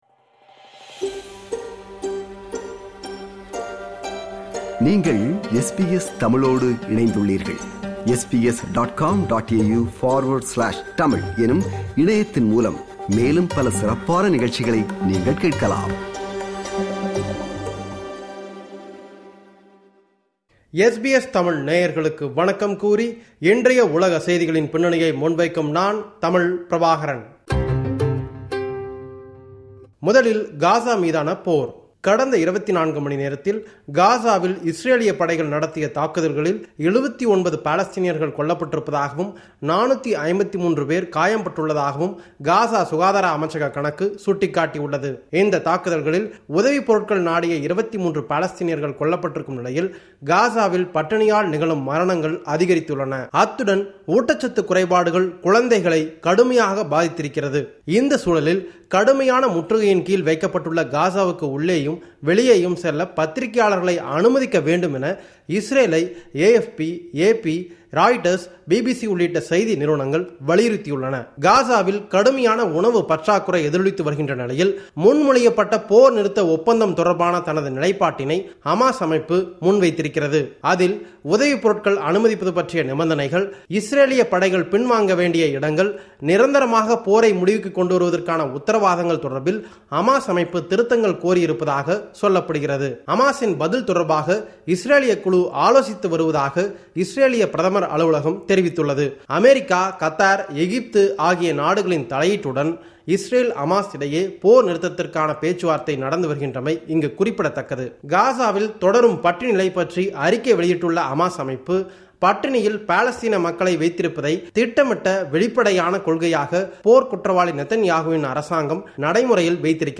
இந்த வார உலக செய்திகளின் தொகுப்பு